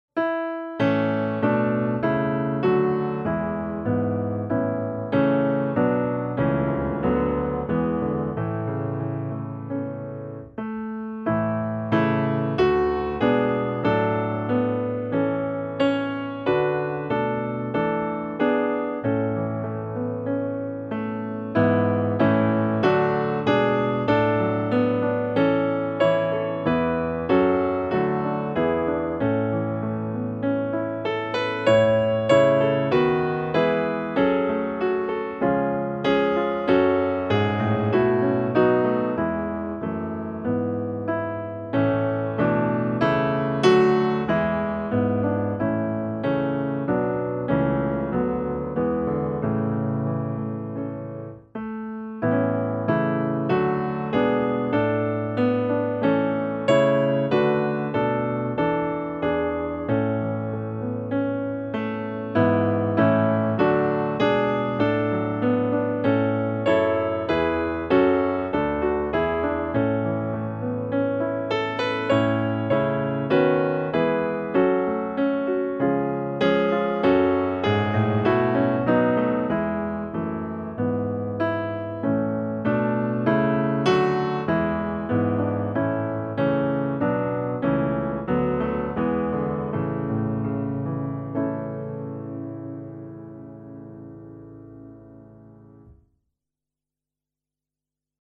Vi lyfter våra hjärtan - musikbakgrund